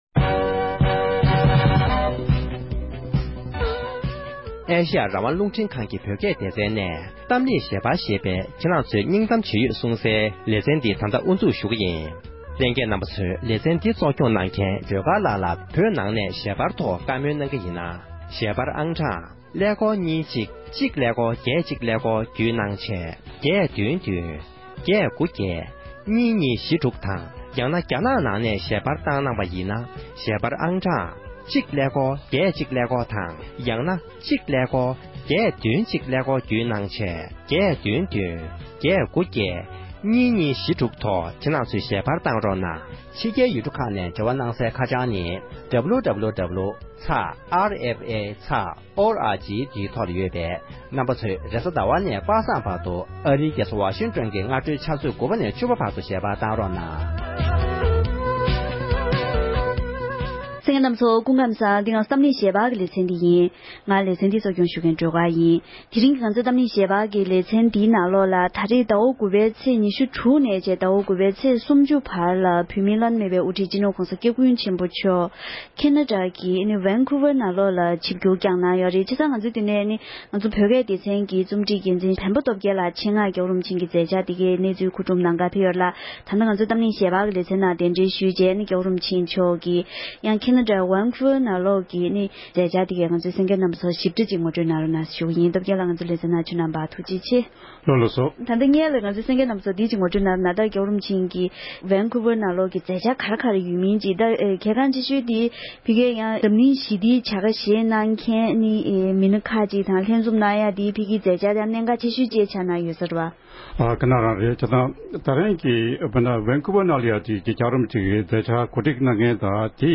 འབྲེལ་ཡོད་མི་སྣའི་ལྷན་གླེང་མོལ་གནང་བའི་ལེ་ཚན་དང་པོར་གསན་རོགས༎